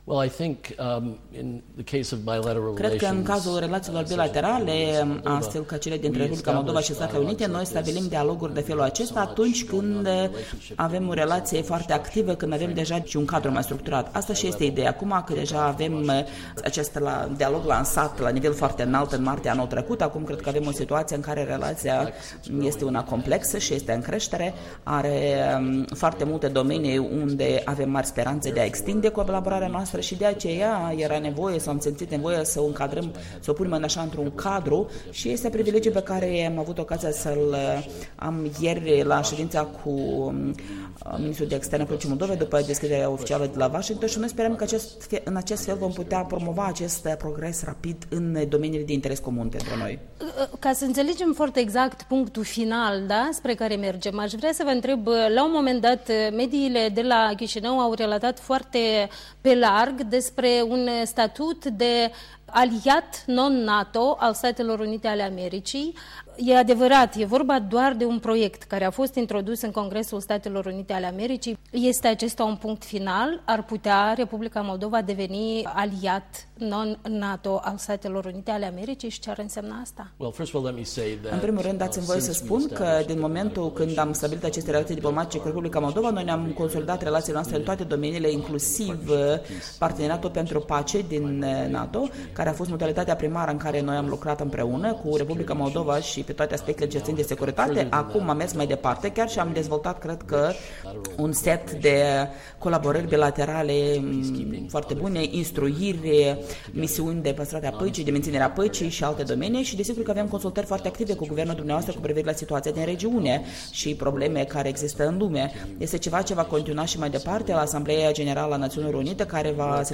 Interviu cu asistentul adjunct al secretarului de stat al SUA, Eric Rubin